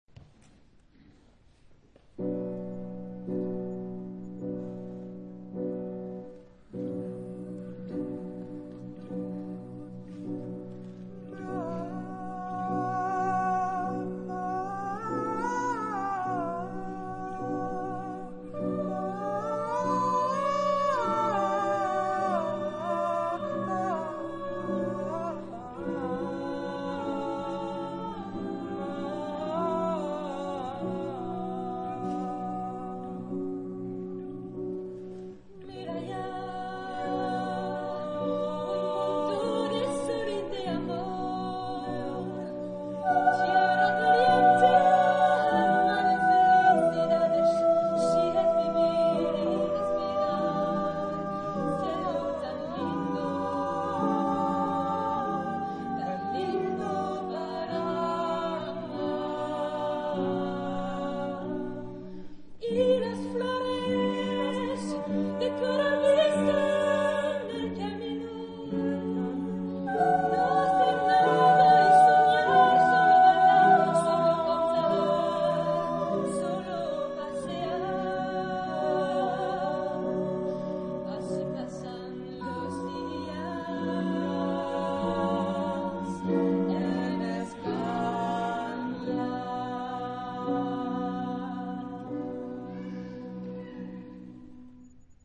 Genre-Style-Form: Choral jazz
Type of Choir: SMATB  (7 mixed voices )
Instrumentation: Piano  (1 instrumental part(s))